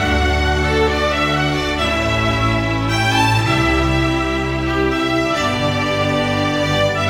Rock-Pop 01 Strings 02.wav